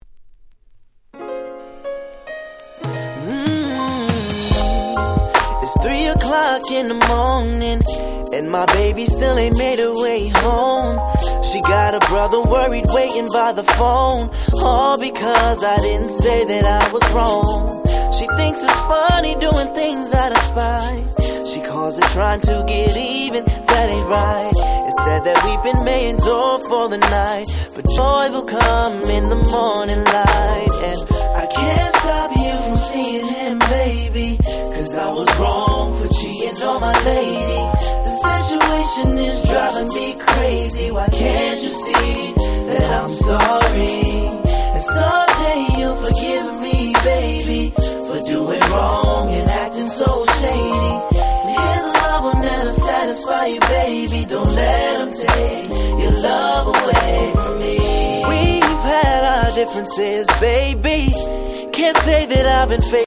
07' Nice UK R&B !!